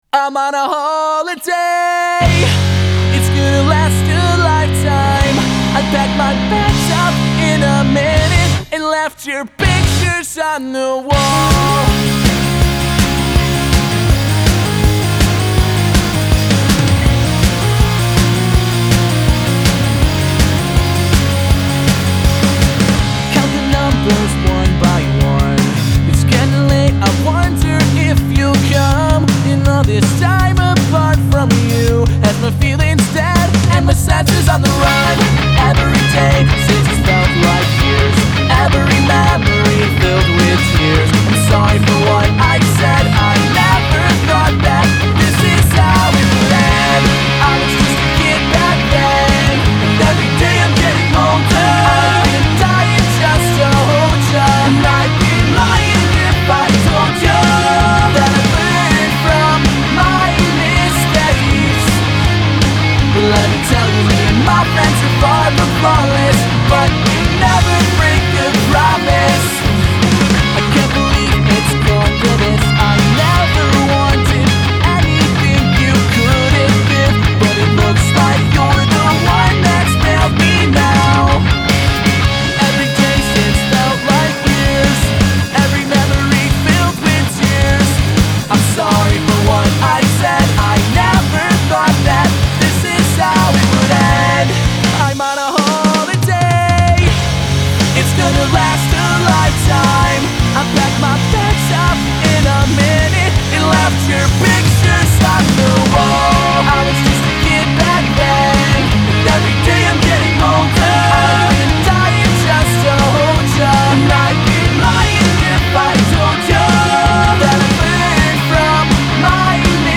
Genre: Emo.